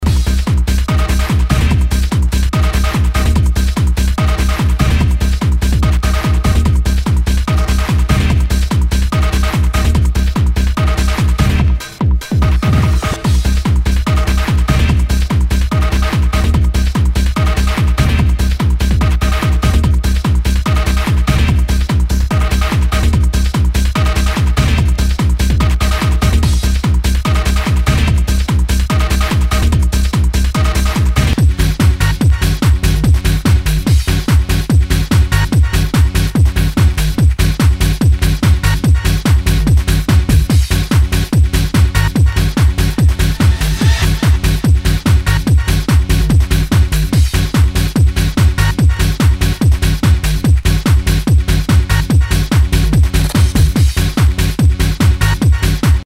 HOUSE/TECHNO/ELECTRO
ナイス！ハード・ハウス！
全体にチリノイズが入ります。